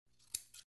Патрон для снайперской винтовки вставили в ствол